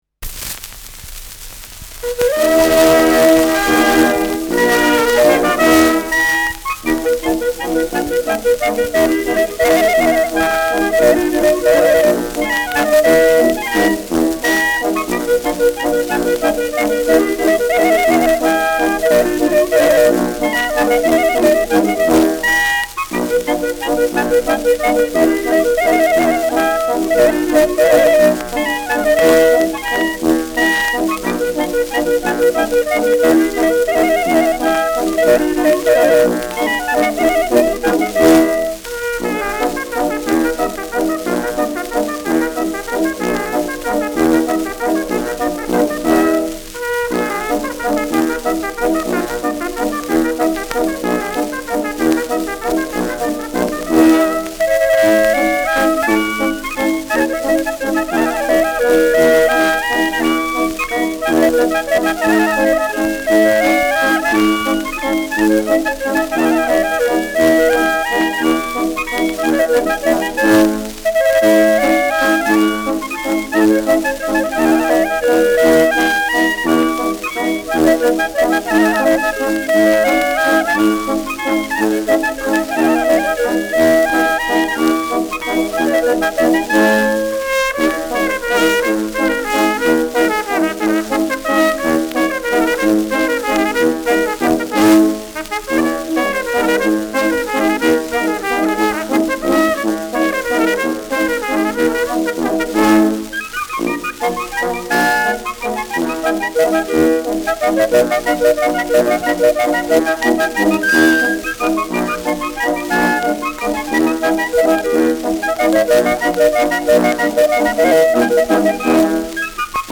Schellackplatte
leichtes Rauschen : gelegentliches Knacken : präsentes Knistern